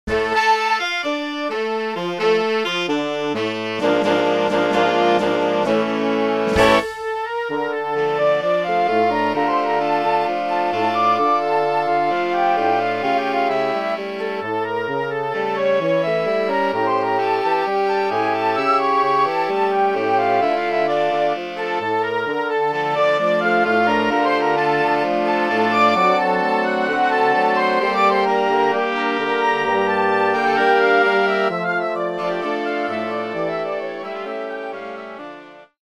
taneczna